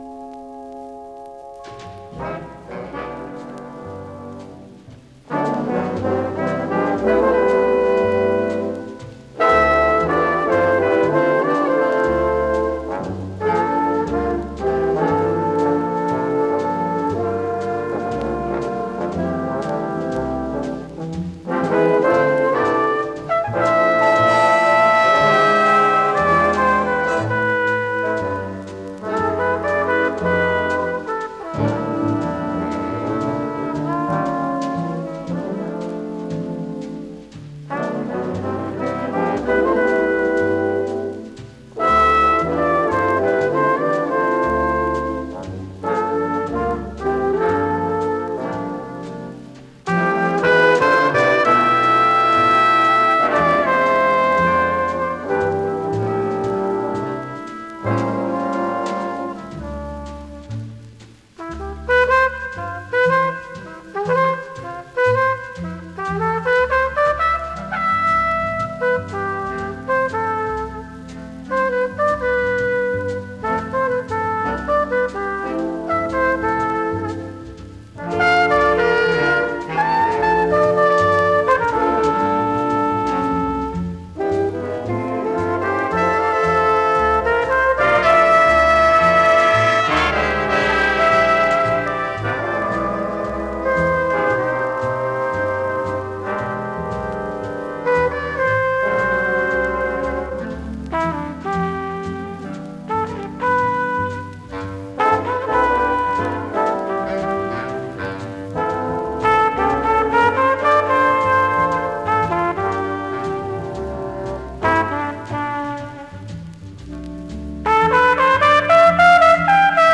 全体的に軽いチリチリ・ノイズ。
MONO盤です。